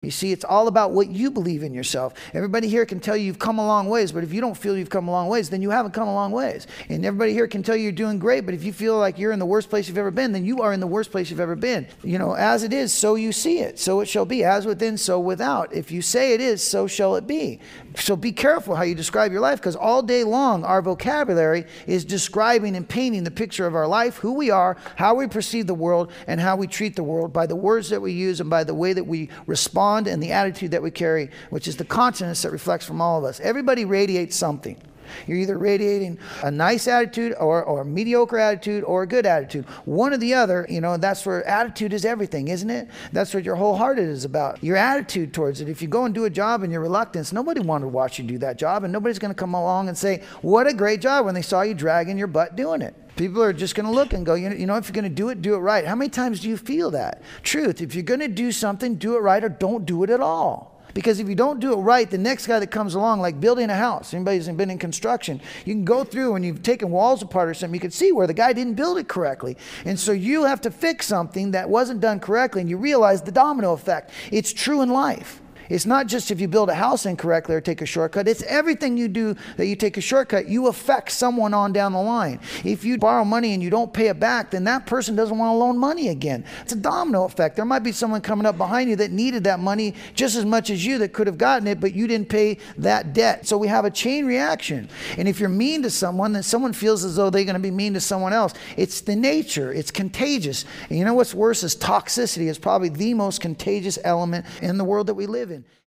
A recovery workshop exploring Path 4, emotional reactions, and how distorted instincts linked to addictionism affect everyday behavior, relationships, and self-awareness.\n\n
This audio archive is a compilation of many years of lecturing.